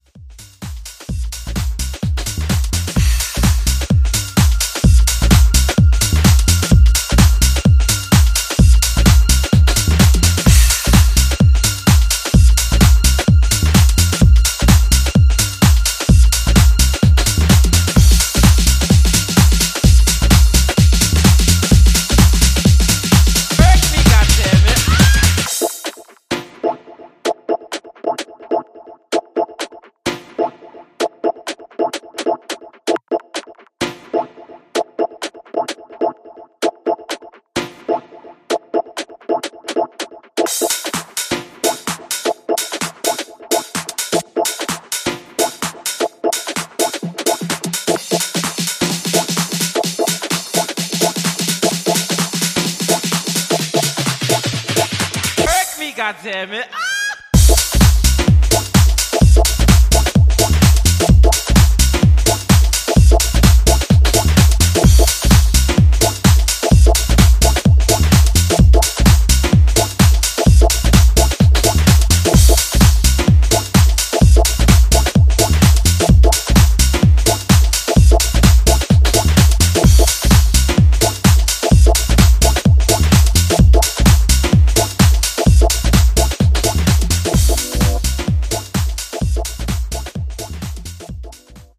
American producer
contemporary underground house
from Disco to Jackin to Soulful To Deep Sounds.